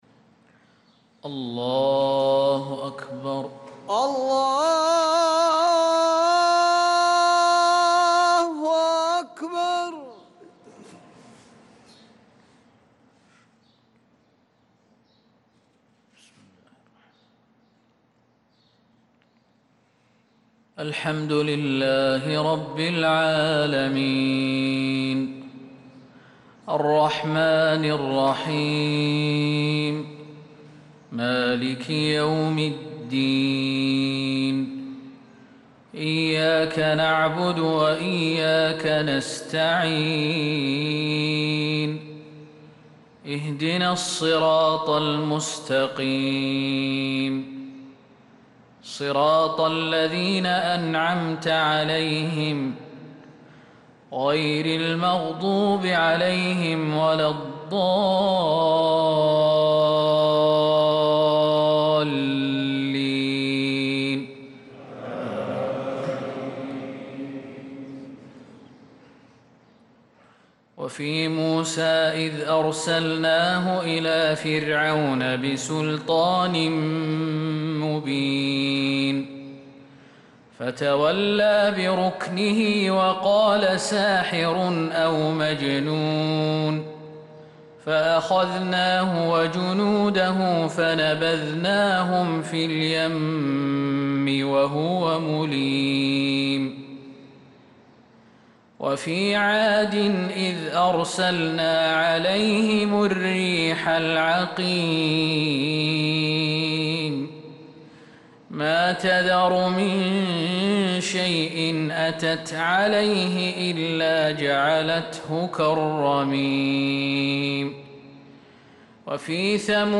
صلاة الفجر للقارئ خالد المهنا 11 ذو الحجة 1445 هـ